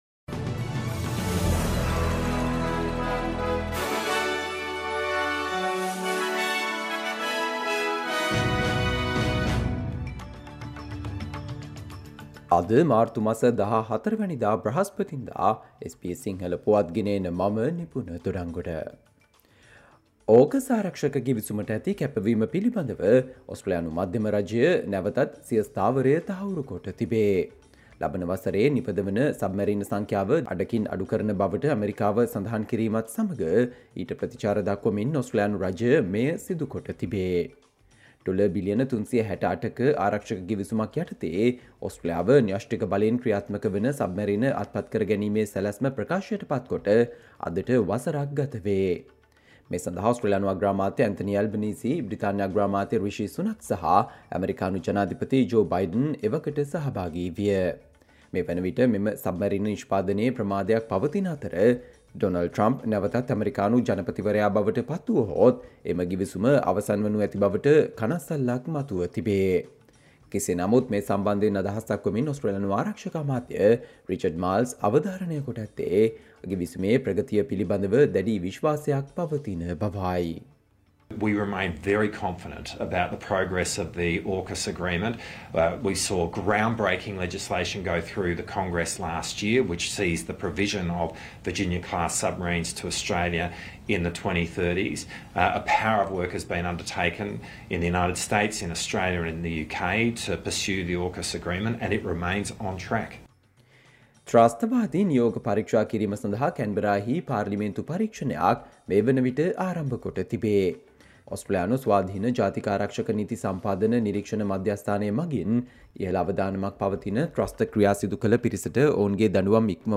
Australia news in Sinhala, foreign and sports news in brief - listen, Thursday 14 March 2024 SBS Sinhala Radio News Flash